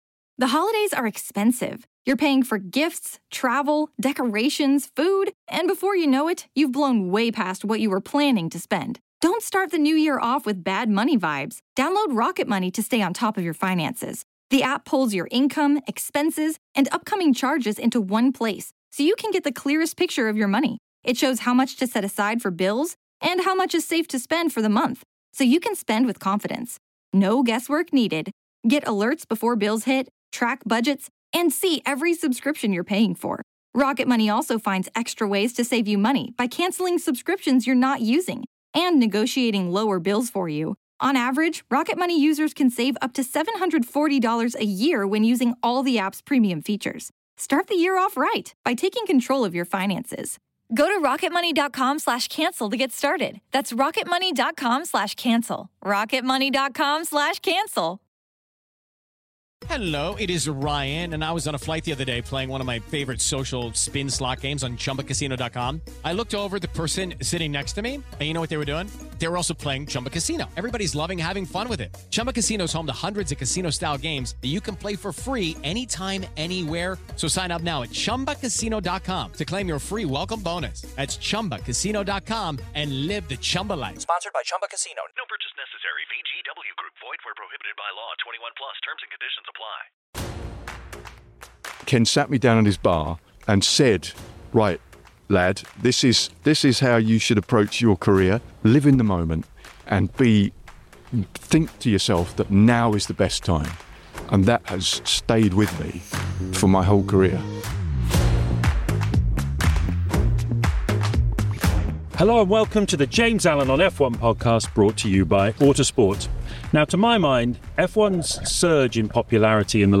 This revealing interview takes the audience behind the veil of F1 and offers a fresh, close-up take of the people that make our sport.